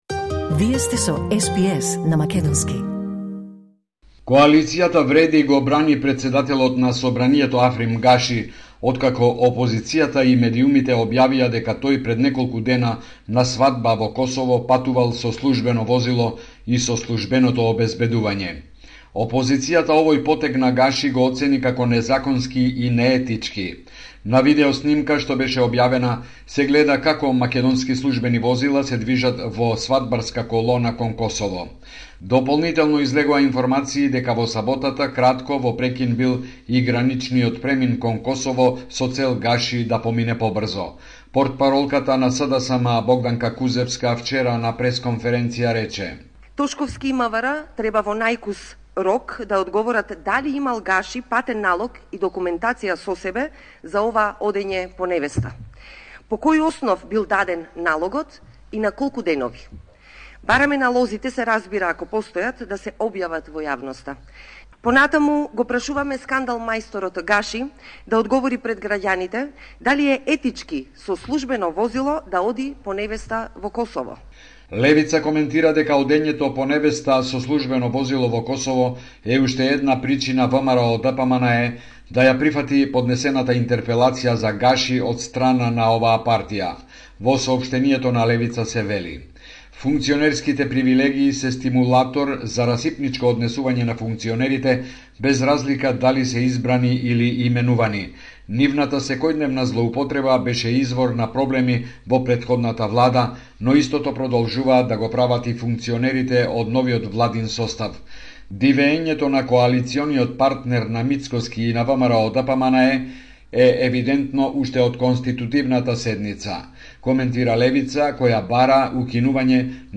Извештај од Македонија 20 август 2024